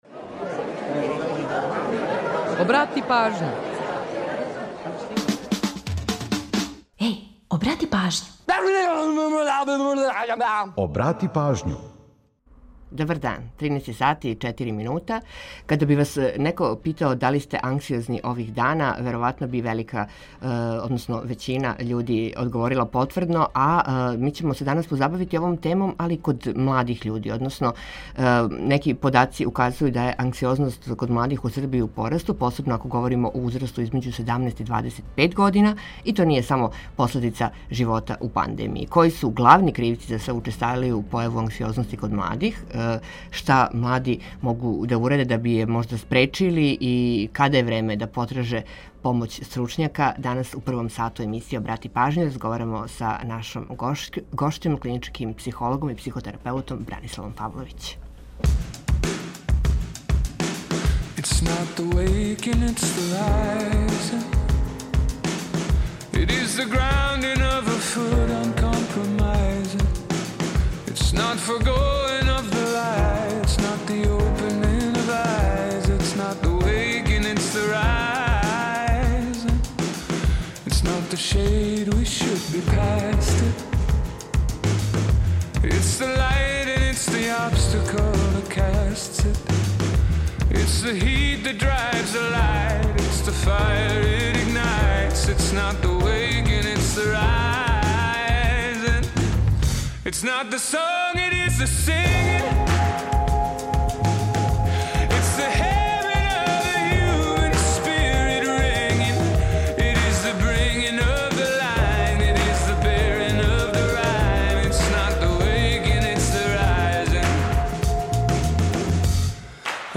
У наставку емисије, подсетићемо вас на важне догађаје у поп рок историји који су догодили на данашњи дан. Ту је и пола сата резервисаних за домаћицу, музику из Србије и региона, прича о једној песми и низ актуелних занимљивости и важних информација.